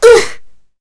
Kara-Vox_Damage_kr_01.wav